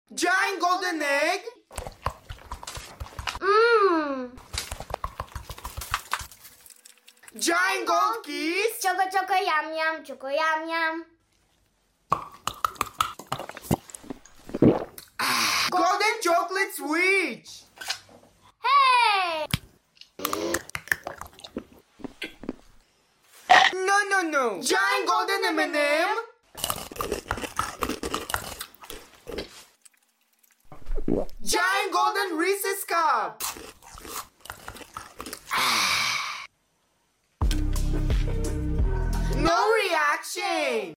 Giant Golden Chicolates ASMR With Sound Effects Free Download